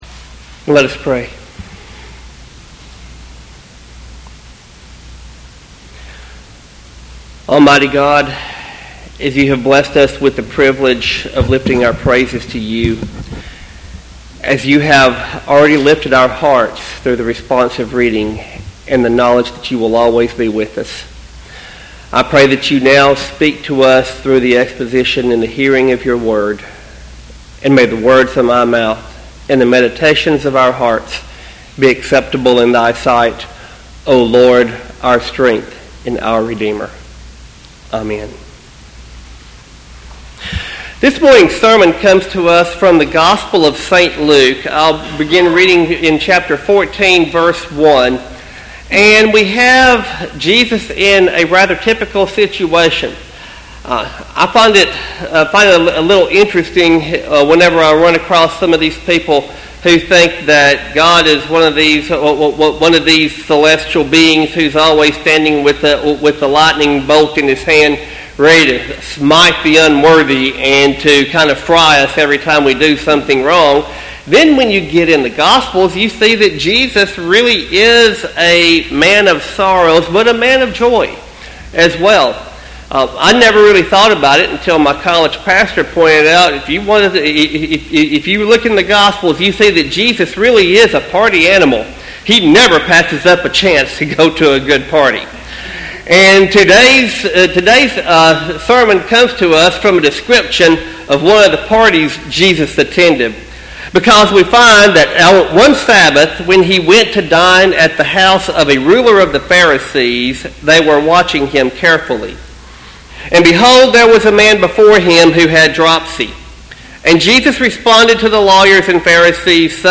Sermon text: Luke 14:1-14.